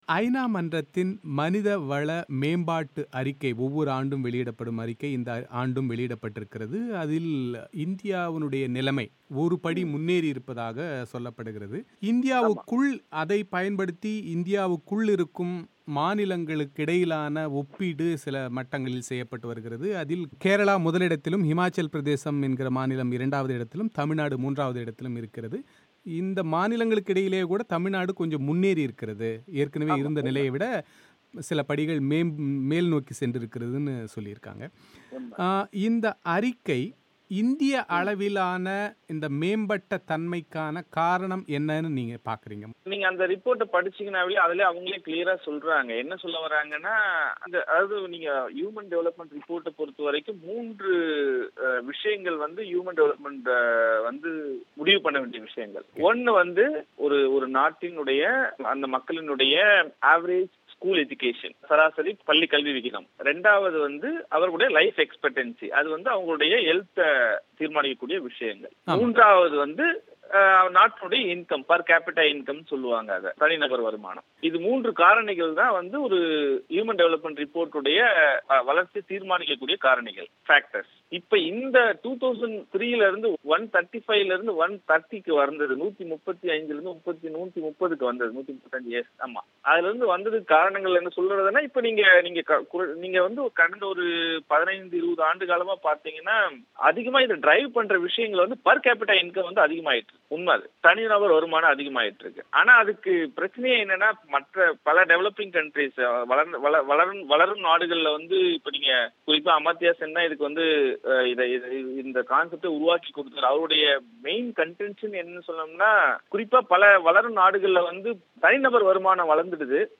தமிழகத்தில் சூரிய ஒளி மின்சார உற்பத்தித்துறையின் பிரச்சனைகள் குறித்து அவர் பிபிசி தமிழோசைக்கு அளித்த விரிவான செவ்வியை நேயர்கள் இங்கே கேட்கலாம்.